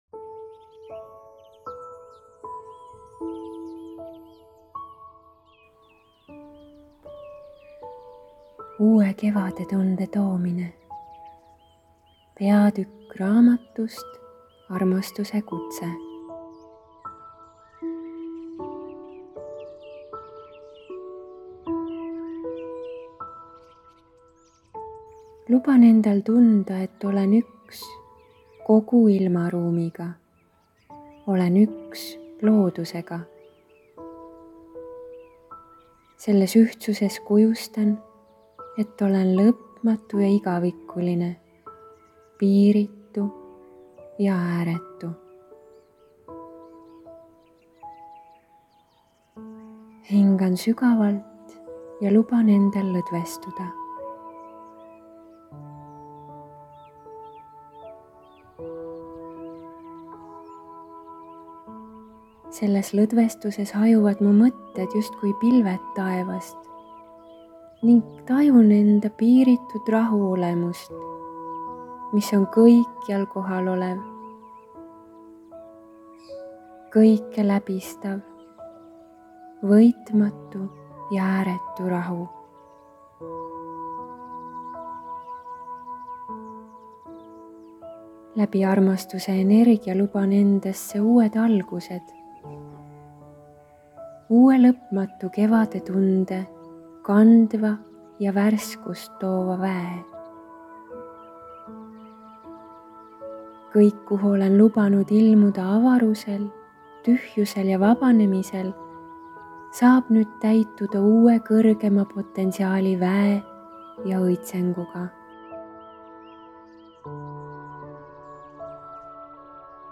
Enda eest hoolitsemise oskuse suurendamise meditatsioon